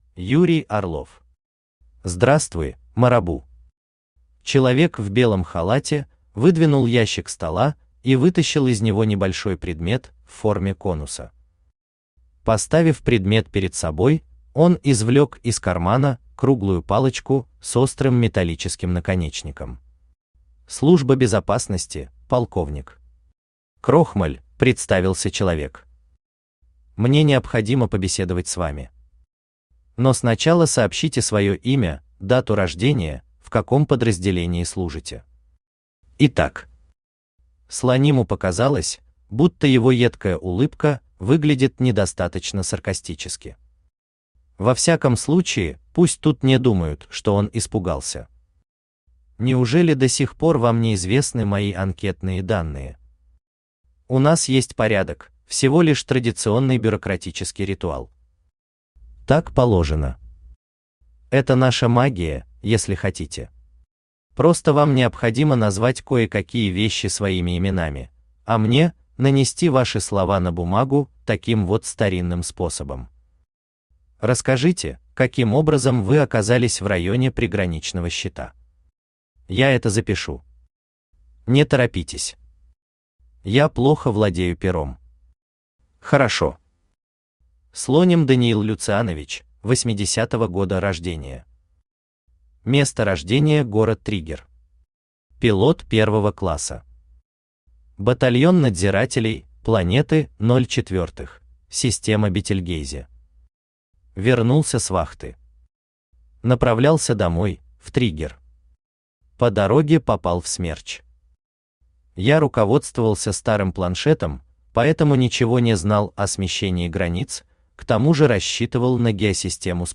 Аудиокнига Здравствуй, Марабу!
Автор Юрий Владимирович Орлов Читает аудиокнигу Авточтец ЛитРес.